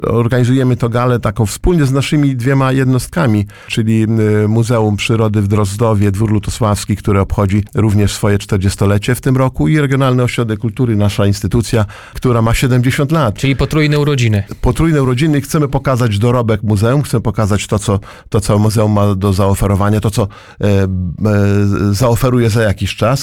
Starosta łomżyński, Lech Szabłowski mówił na naszej antenie, że podczas gali przypomniane zostaną najważniejsze i najciekawsze momenty z ostatnich 25 lat.